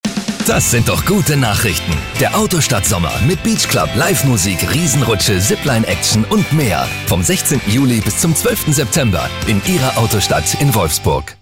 Seriös und überzeugend in Dokumentationen, Features, Industriefilmen und Multimedia. Frisch und engagiert in Werbung, Synchron, Hörspiel und Hörbuch.
TV Voice Over